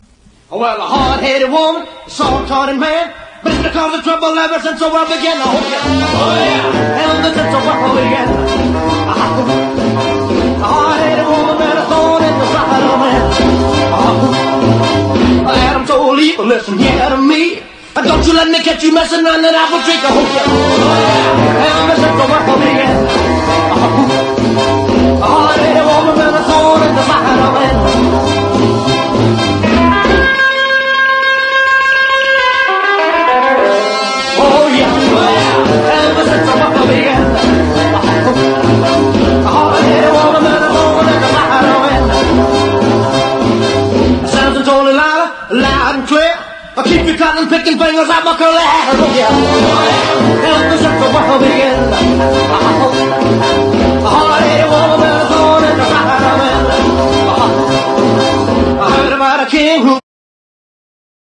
EASY LISTENING / VOCAL / CHANSON / POLISH